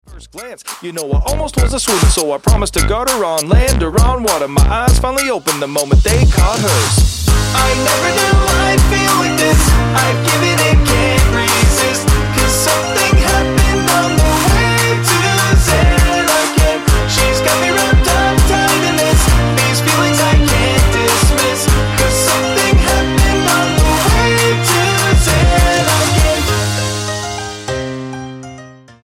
STYLE: Hip-Hop
intentionally simple old skool vocal flows